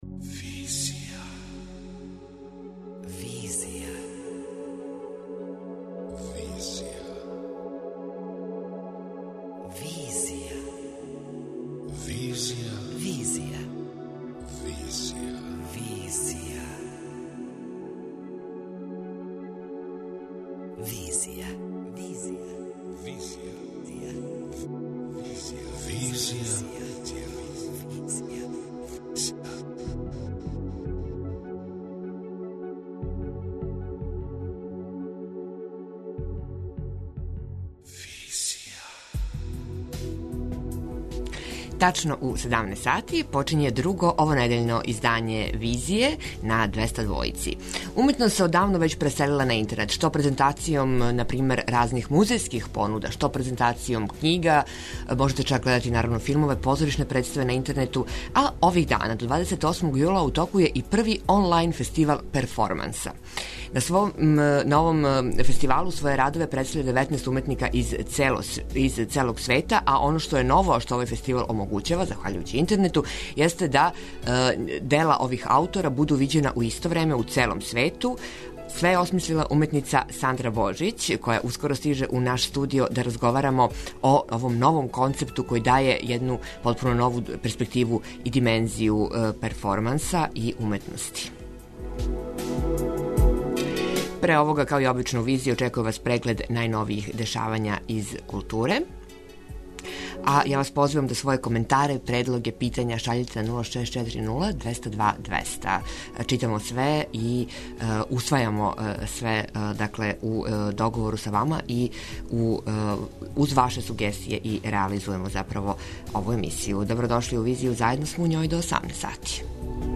преузми : 27.13 MB Визија Autor: Београд 202 Социо-културолошки магазин, који прати савремене друштвене феномене.